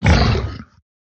1.21.5 / assets / minecraft / sounds / mob / zoglin / hurt2.ogg
hurt2.ogg